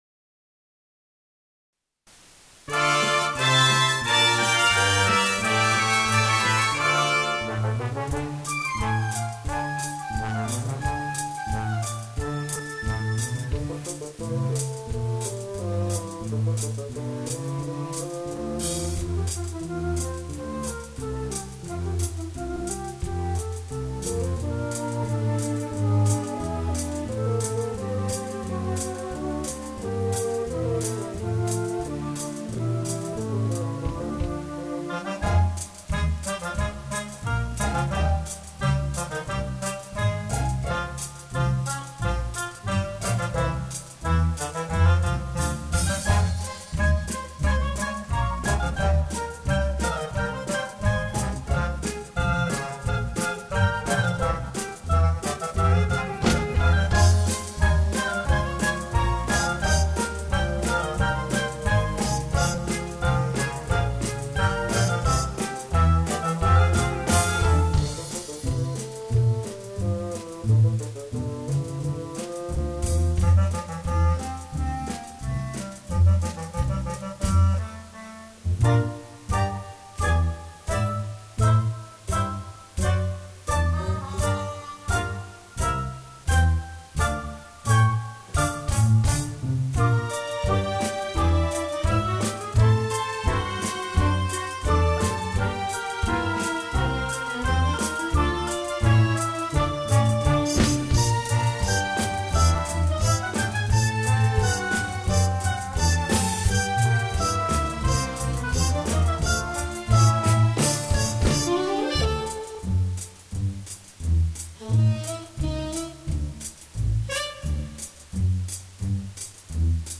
Rhythm Of Life – Backing | Ipswich Hospital Community Choir
Rhythm-Of-Life-Backing.mp3